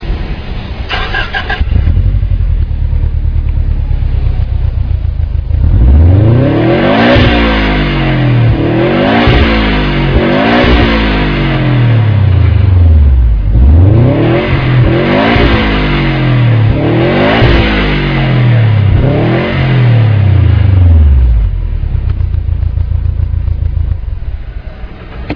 Impreza with sport exhaust (Realplayer 50Ko)